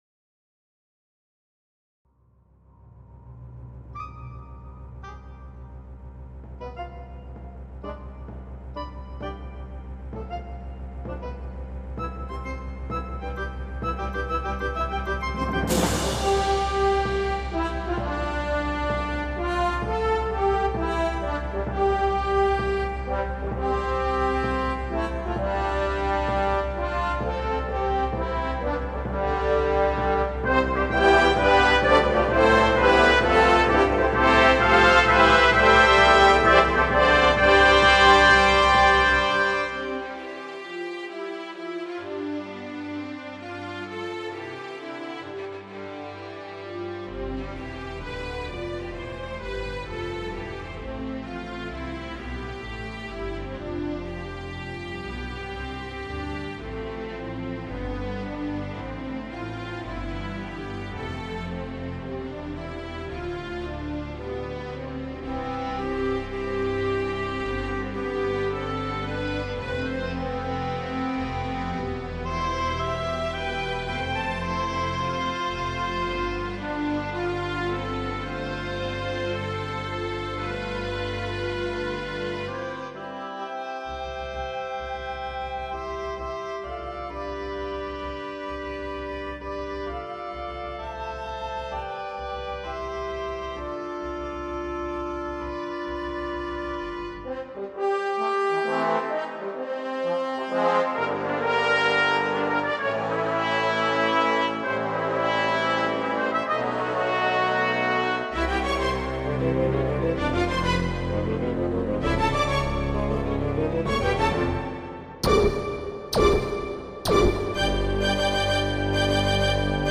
The mood of the music is either action or emotion.